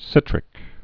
(sĭtrĭk)